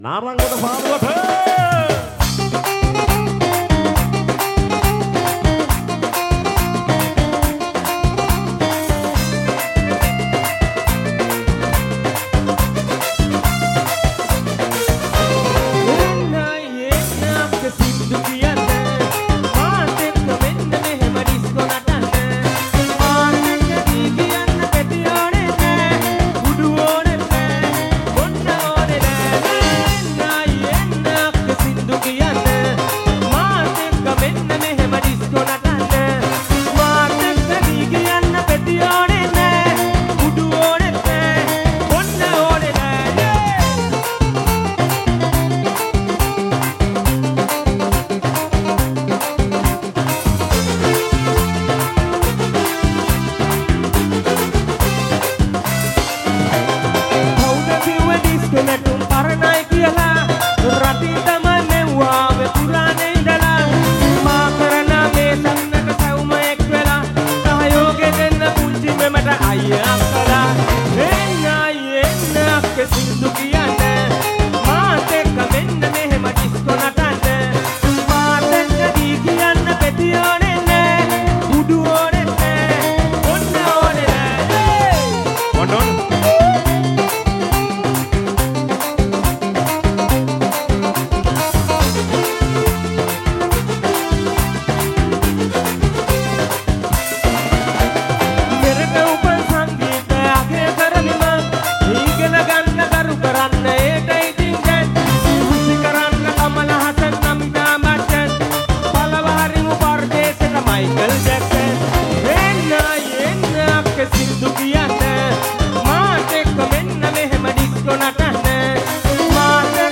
Live in Narangodapaluwa 2024-04-24